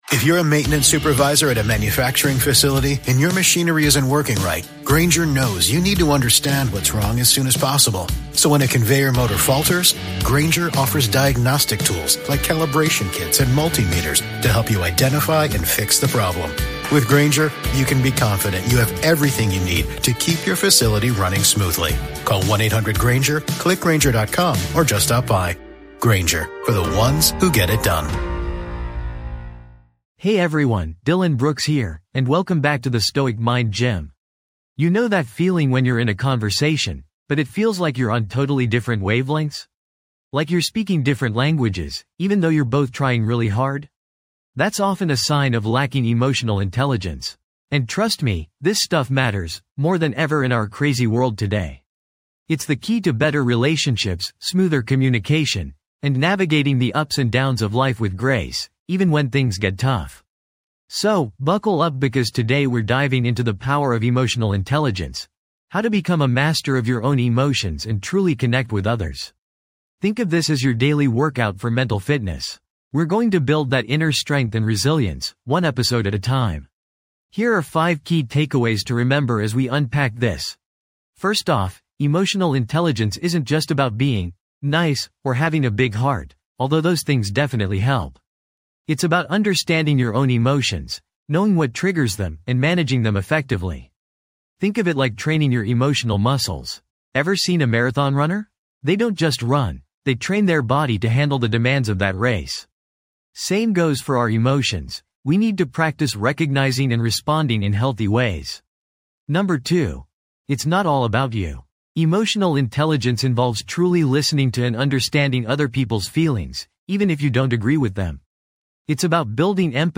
Self-Help & Personal Growth
This podcast is created with the help of advanced AI to deliver thoughtful affirmations and positive messages just for you.